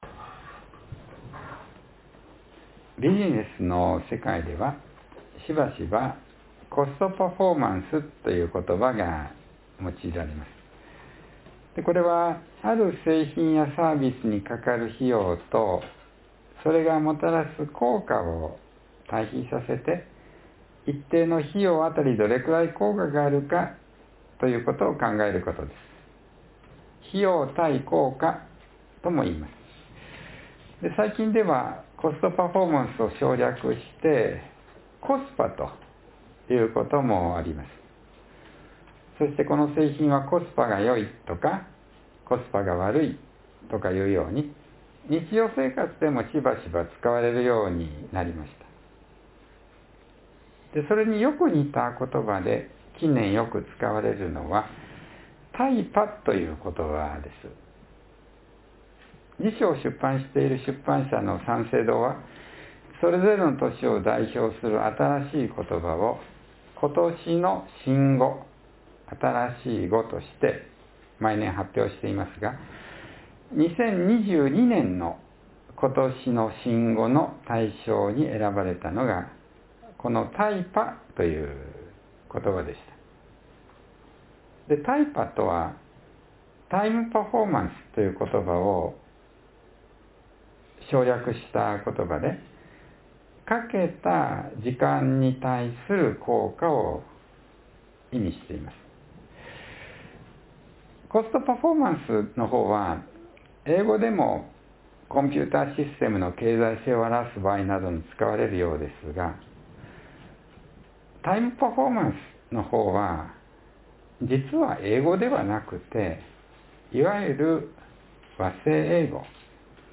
（2月22日の説教より）